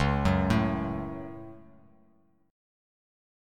C#sus4#5 chord